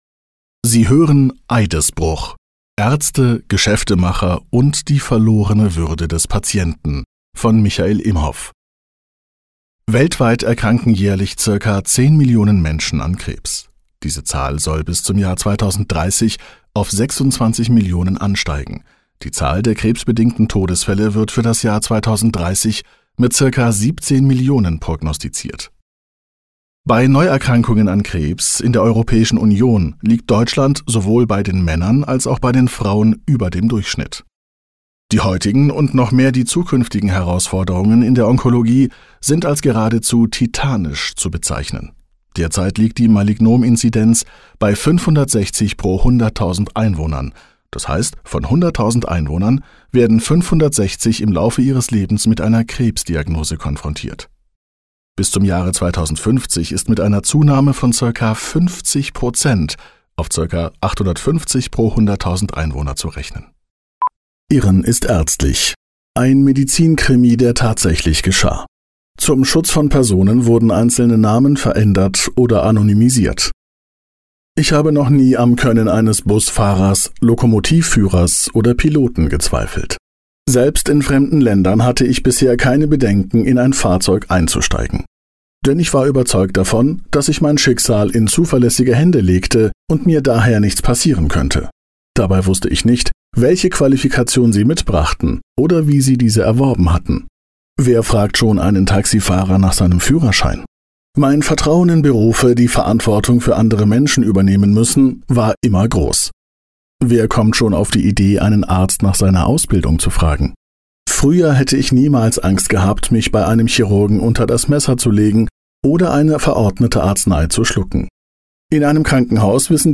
Male
Approachable, Assured, Bright, Character, Confident, Conversational, Cool, Corporate, Deep, Energetic, Engaging, Friendly, Funny, Natural, Sarcastic, Smooth, Soft, Upbeat, Versatile, Warm
Microphone: Neumann TLM 103